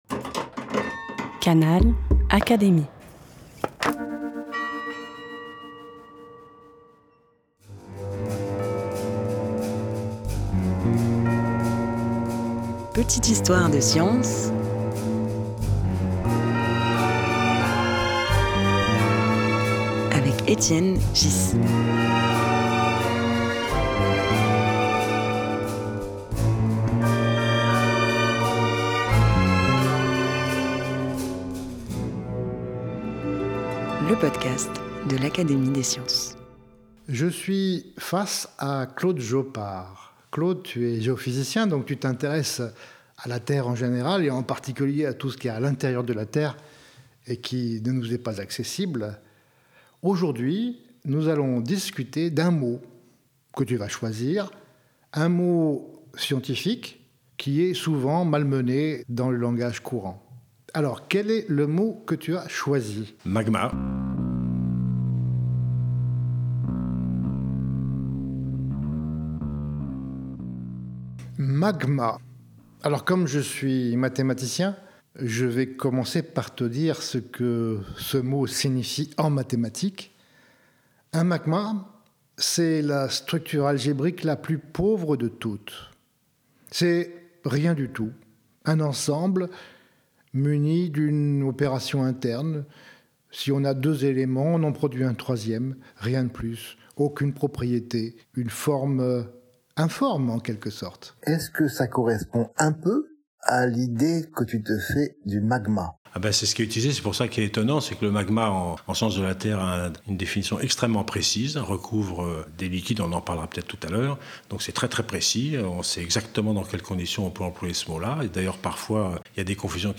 Émission de culture générale. Chaque semaine, un nouvel invité (académicien, chercheur, etc) apporte des éclairages approfondis et nuancés sur un sujet tiré de sa spécialité.